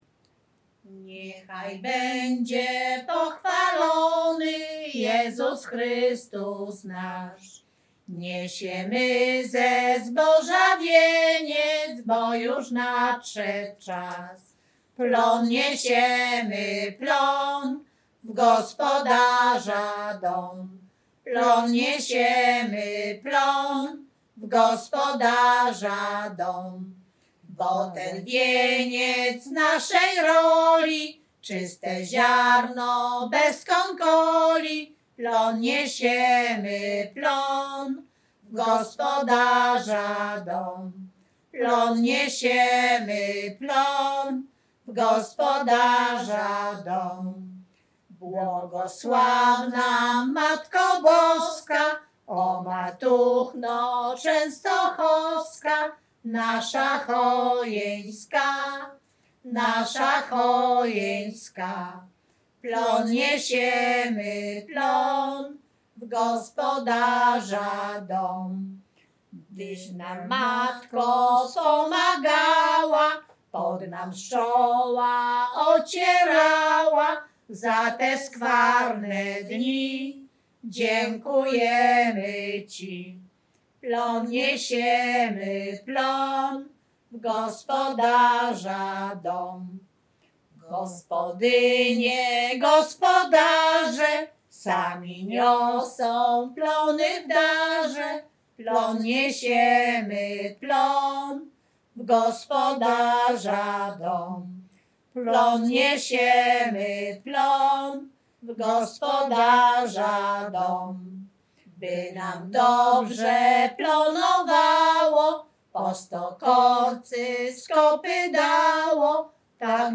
Śpiewaczki z Chojnego
Dożynkowa